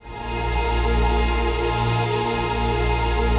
ATMOPAD05.wav